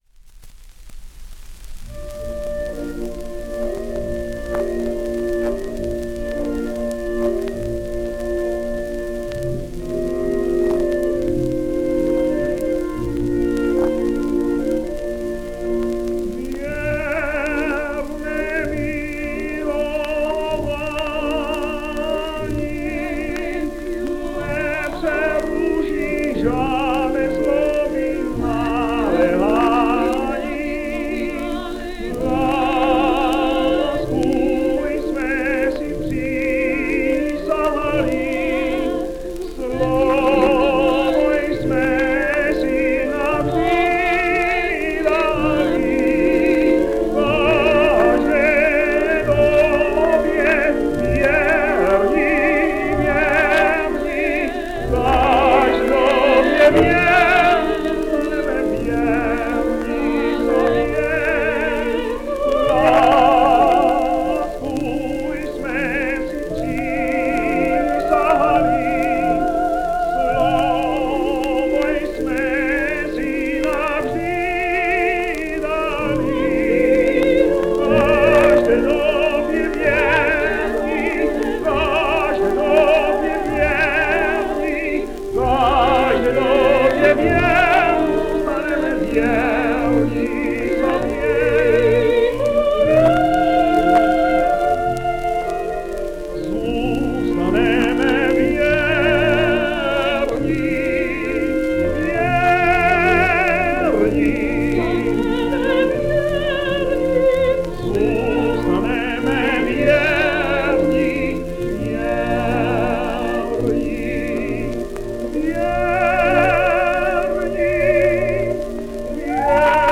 Miloslav Jeník sings Prodaná nevěsta:
After concluding that career in 1910, he briefly studied voice, and made his tenor debut in 1911 in Smichov.